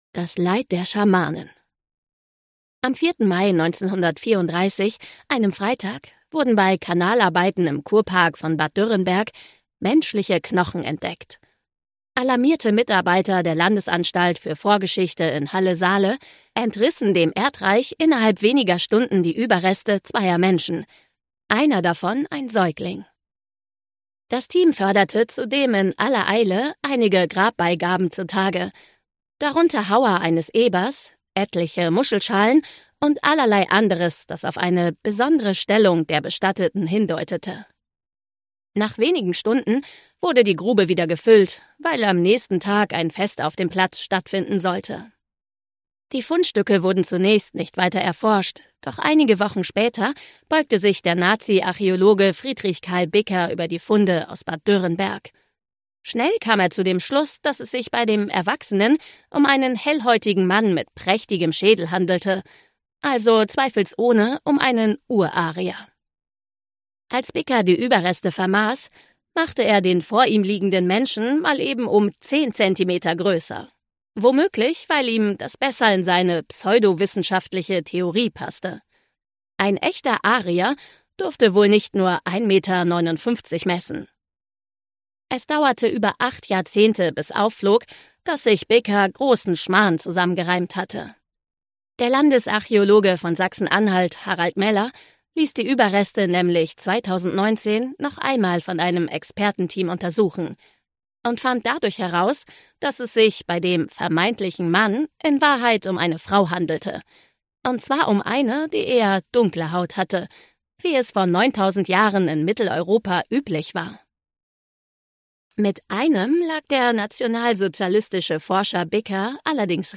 2025 | Ungekürzte Lesung